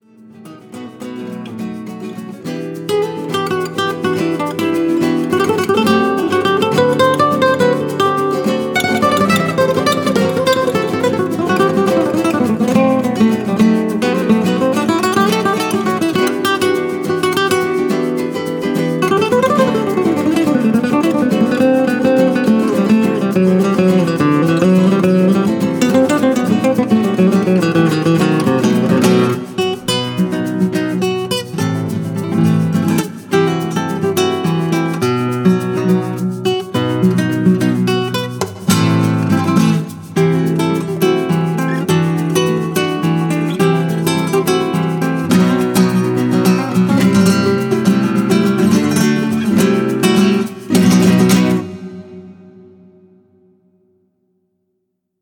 gitarre, bass, percussion
flöte
klarinette
cello